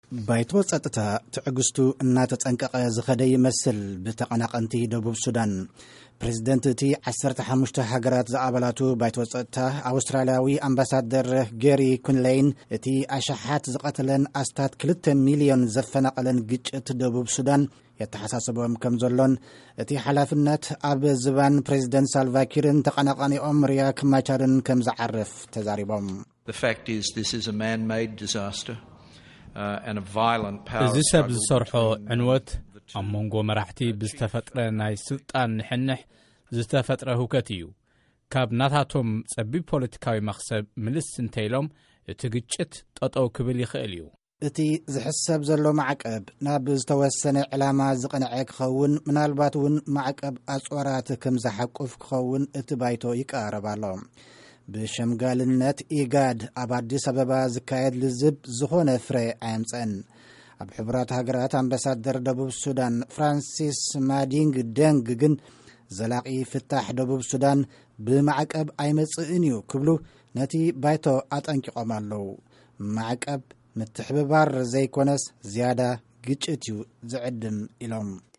ሪፖርት ደቡብ ሱዳን